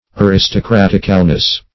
Ar`is*to*crat"ic*al*ness, n.